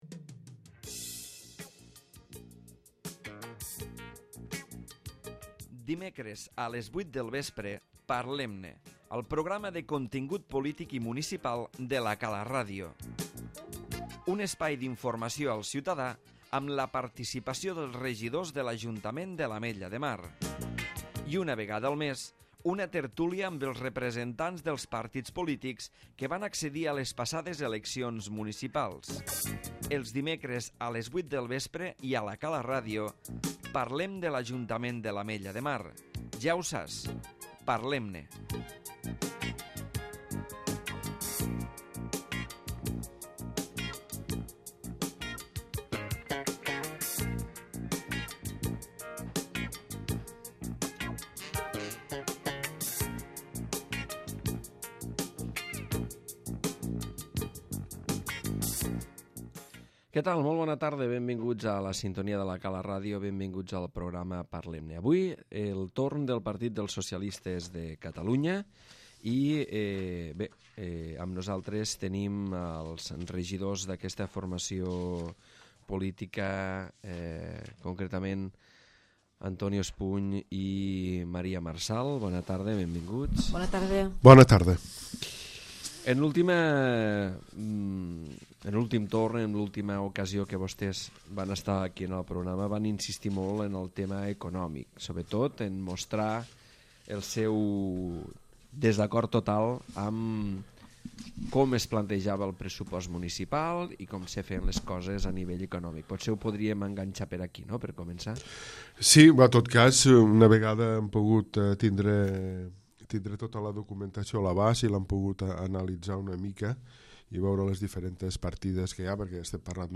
Feb, 2010 a les 20:00 per admin a Parlem-ne 2358 reproduccions Antonio Espuny i Maria Marsal, regidors del Grup Municipal del PSC a l'Ametlla de Mar han parlat en el programa parlem-ne de la situació econòmica municipal i han criticat la politica econòmica de l'equip de govern. En el transcurs del programa els regidors socialistes han donat el seu suport al sector tonyinaire de La Cala i s'han mostrat contraris a la instal·lació de l'MTC a Ascó.